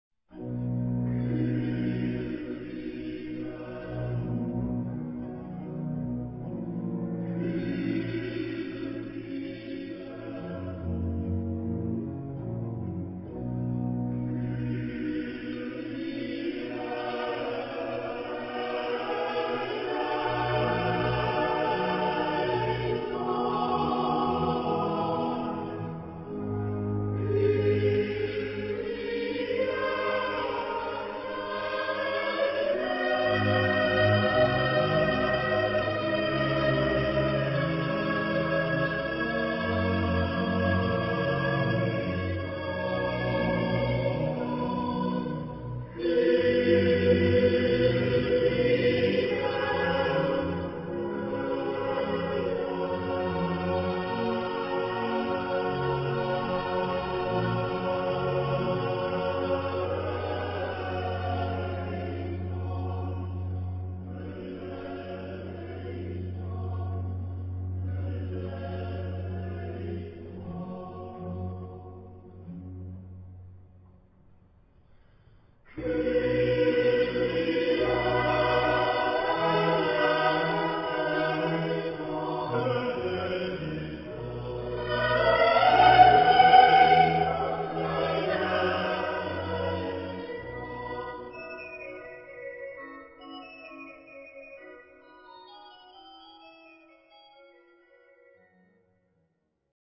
Epoque: 18th century
Genre-Style-Form: Classic ; Sacred ; Mass
Type of Choir: SATB  (4 mixed voices )
Instruments: Double bass (1) ; Organ (1)
Tonality: C major
sung by Tölzer Knabenchor conducted by Gerhard Schmidt-Gaden